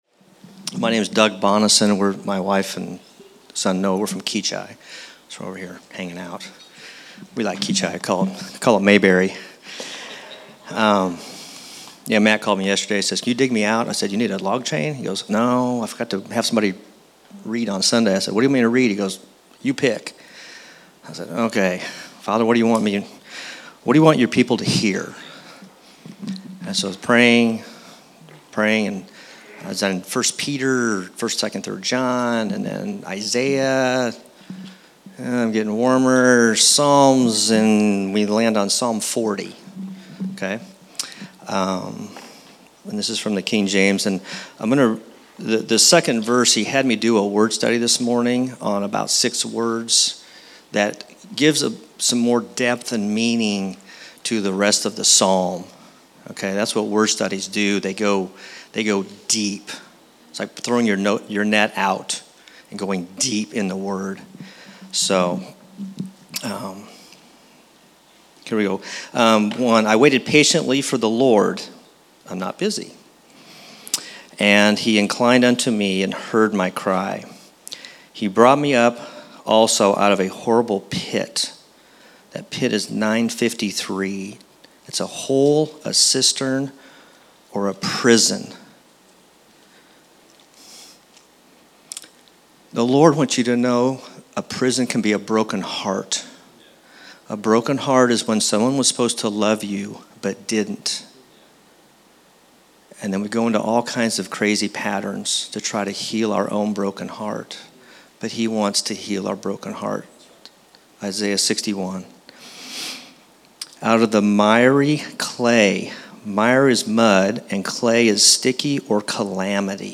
Scripture Reading - Psalm 40
Location: El Dorado